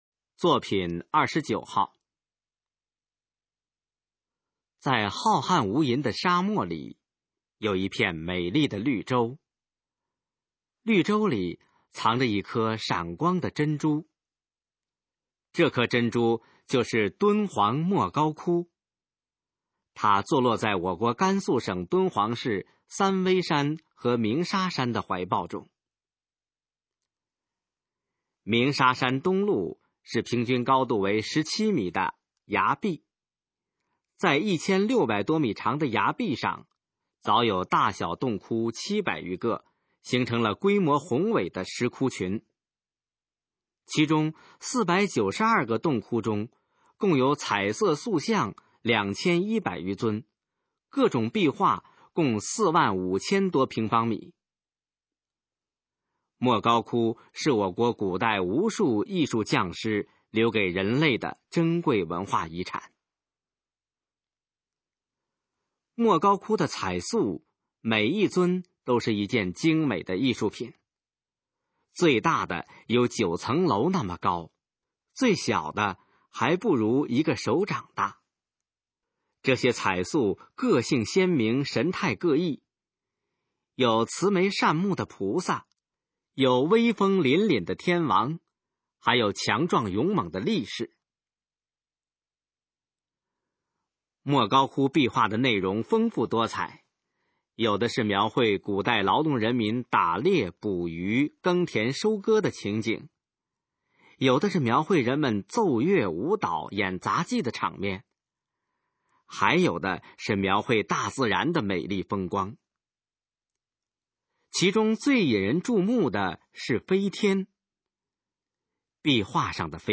《莫高窟》示范朗读_水平测试（等级考试）用60篇朗读作品范读